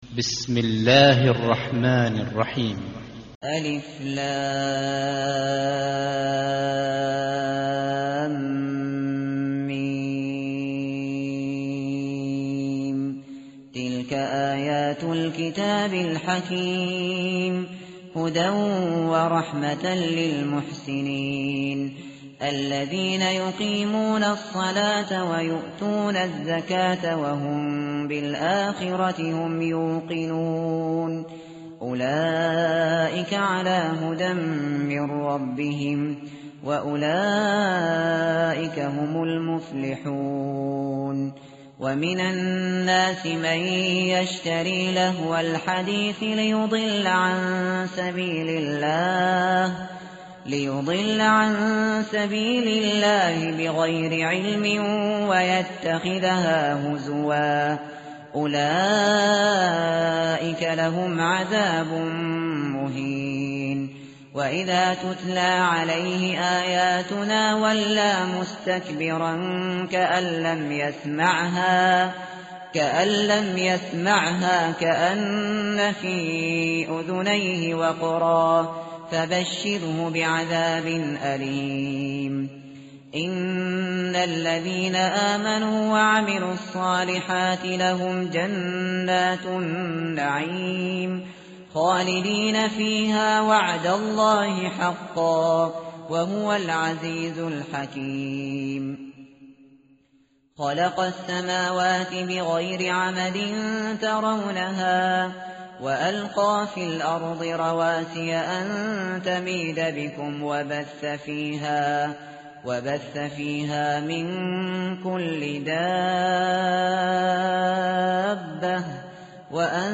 متن قرآن همراه باتلاوت قرآن و ترجمه
tartil_shateri_page_411.mp3